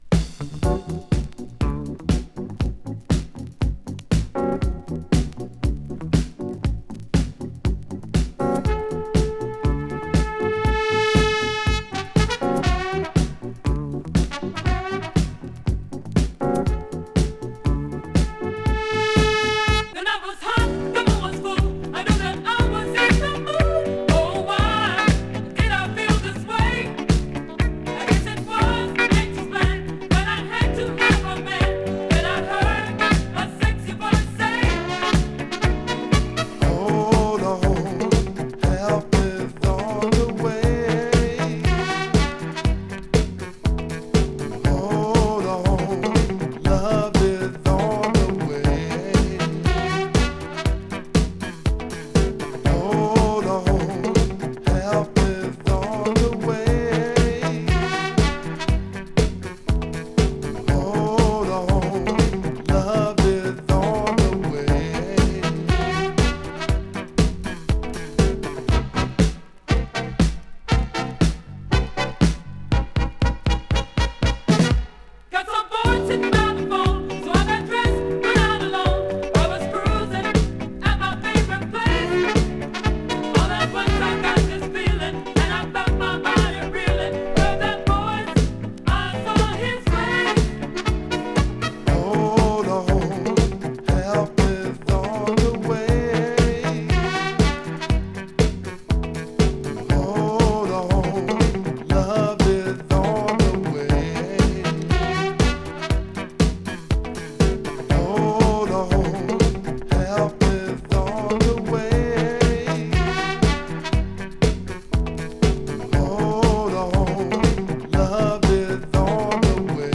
Loft & Garage Classic！
【CANADA】【DISCO】【BOOGIE】